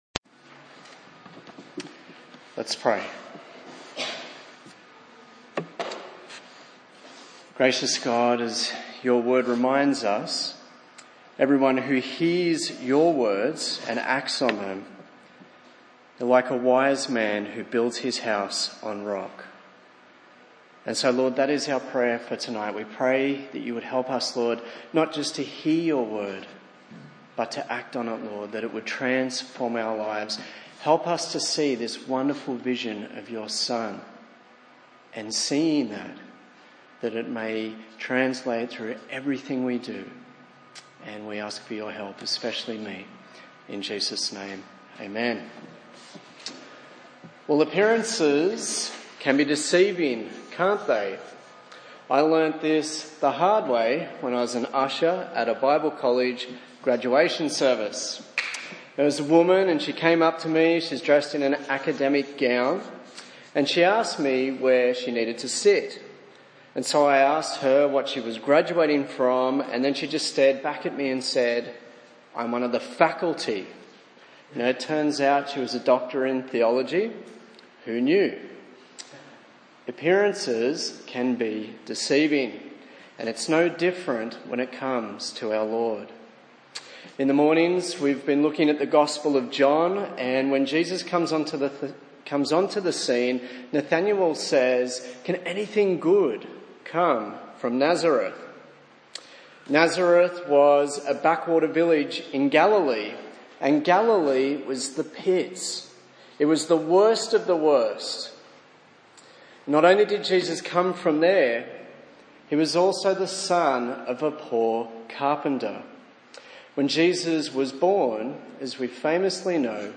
A sermon in the series on Hebrews